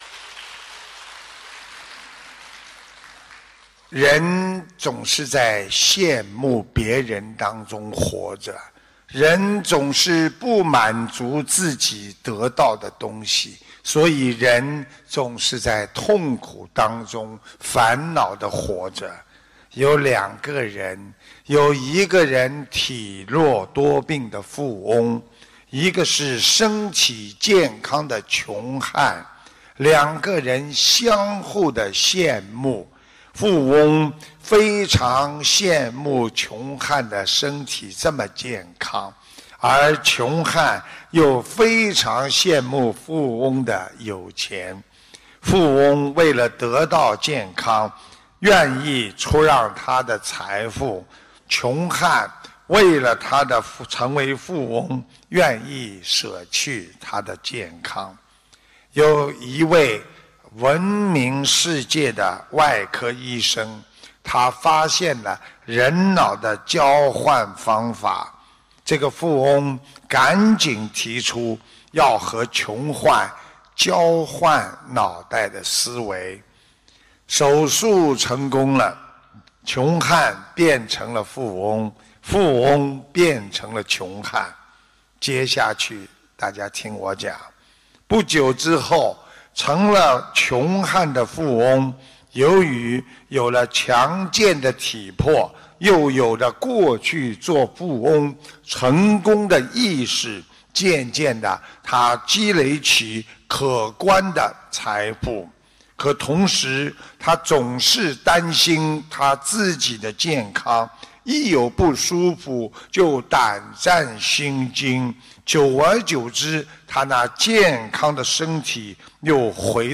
澳大利亚悉尼